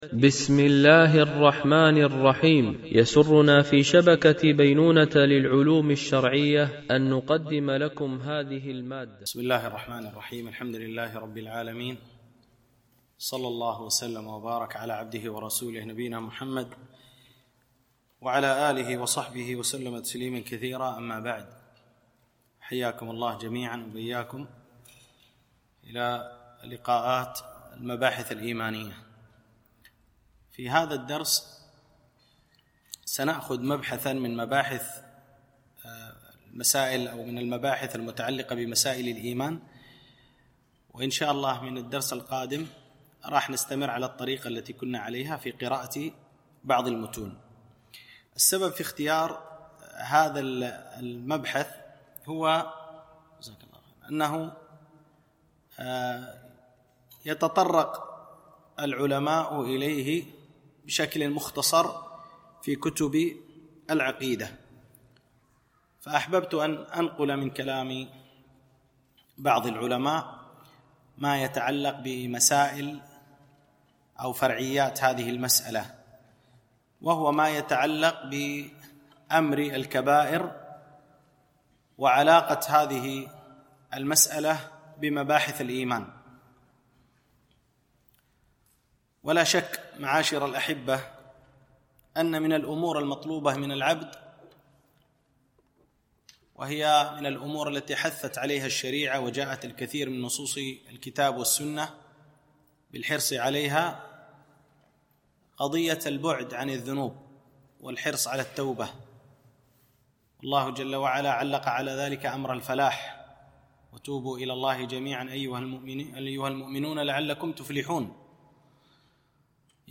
مباحث إيمانية - الدرس 22